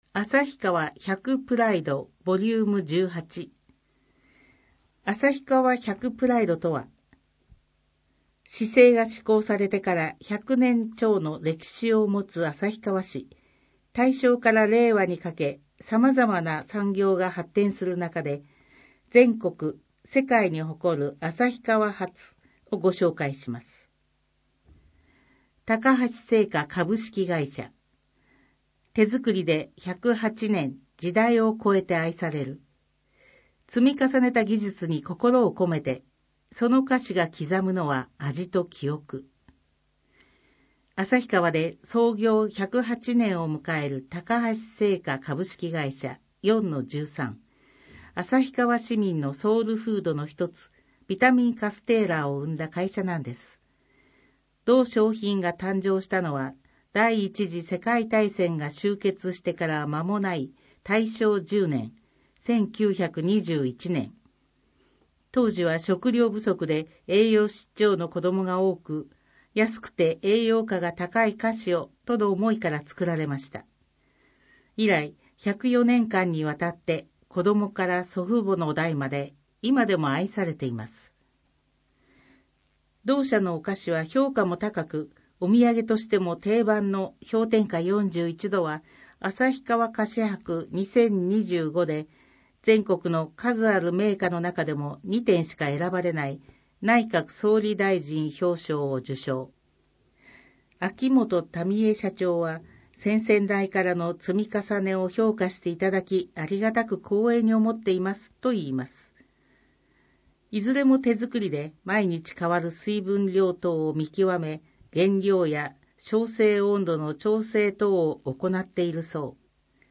広報誌の内容を要約し、音訳した声の広報「あさひばし」を、デイジー図書版で毎月発行しています。